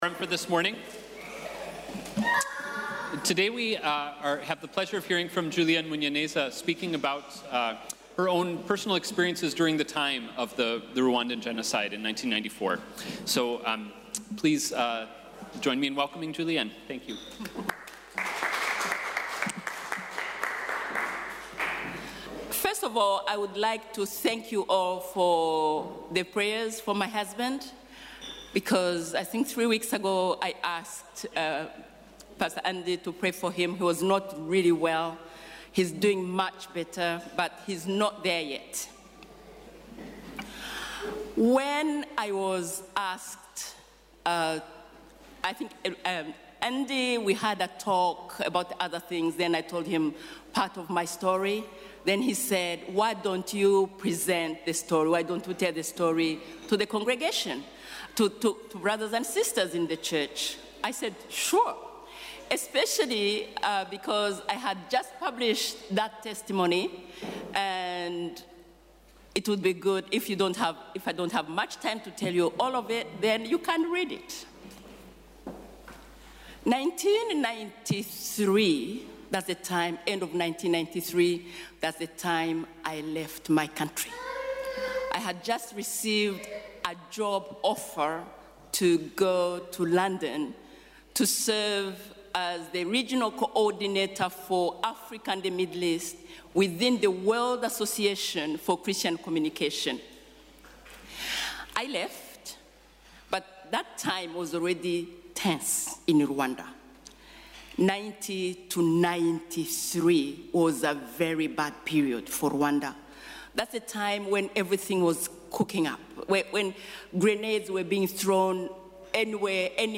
Weekly Forums at ELCG – after worship, in the Sanctuary – 12:45 to 13:30